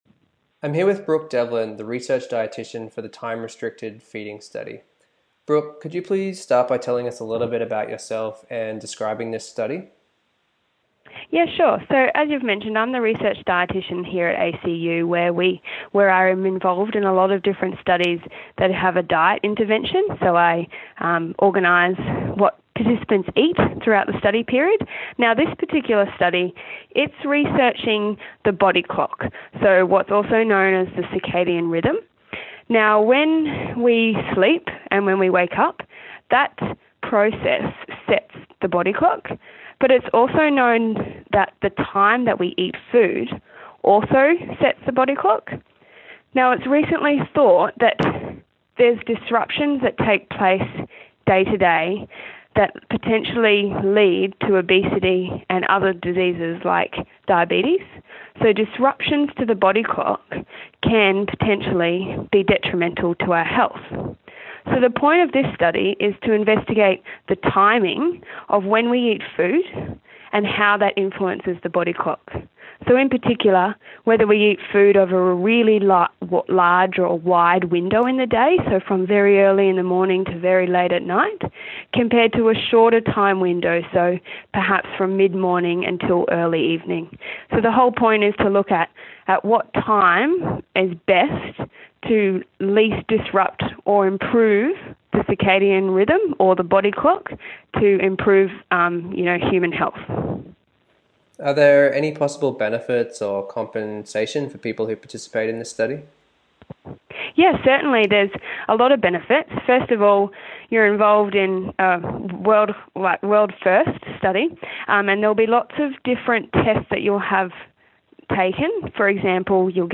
Researcher Interview